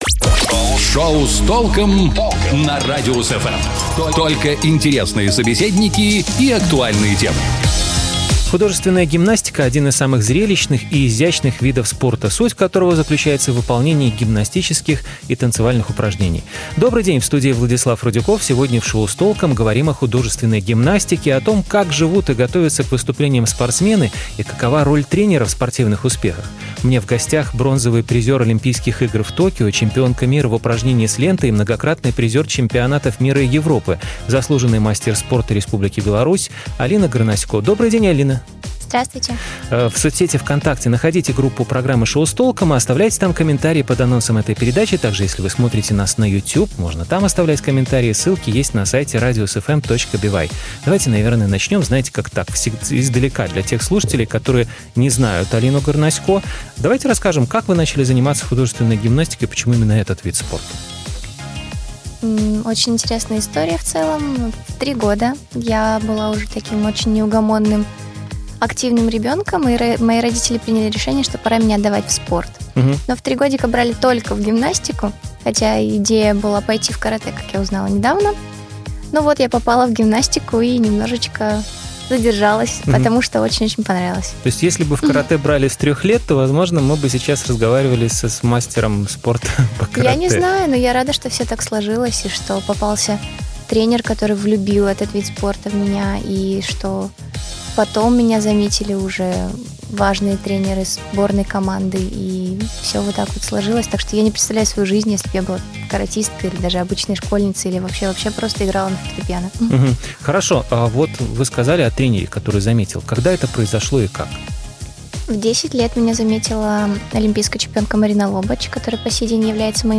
У нас в гостях - бронзовый призер Олимпийских игр в Токио, чемпионка мира в упражнении с лентой и многократный призер чемпионатов мира и Европы, заслуженный мастер спорта Республики Беларуси Алина Горносько.